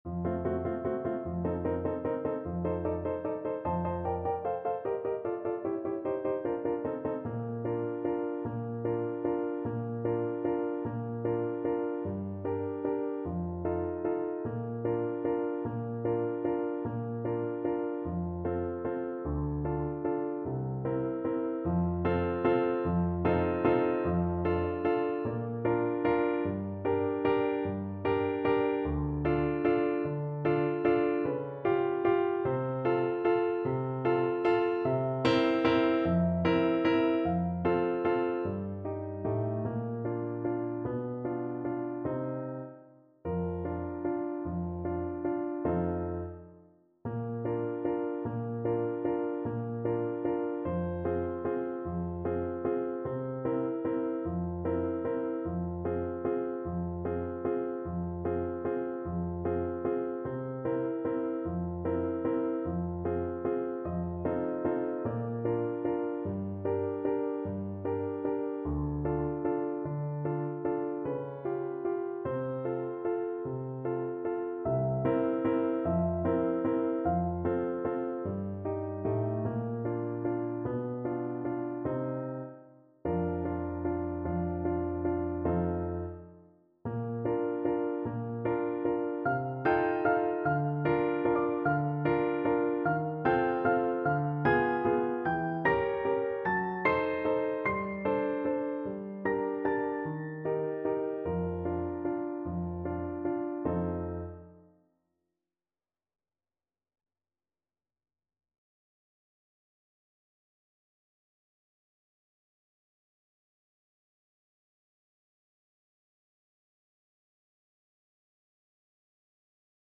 Trombone version
Adagio =50
3/4 (View more 3/4 Music)
Classical (View more Classical Trombone Music)